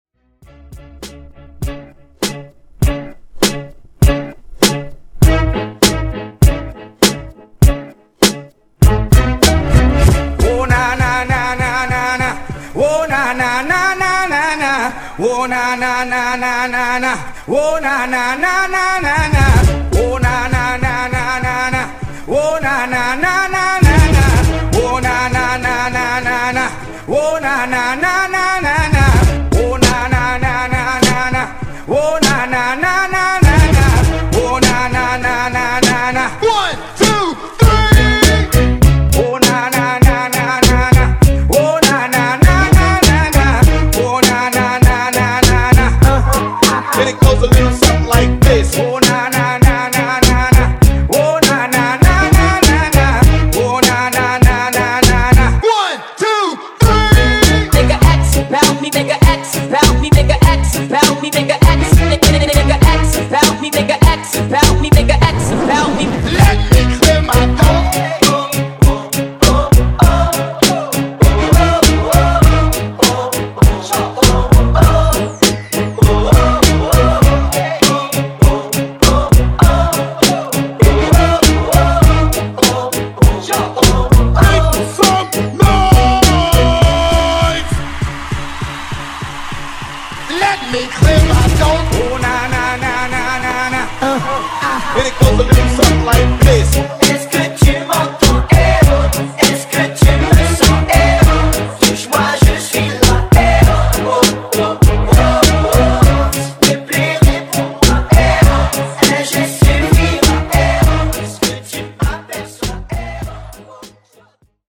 Genres: 2000's , RE-DRUM
Clean BPM: 120 Time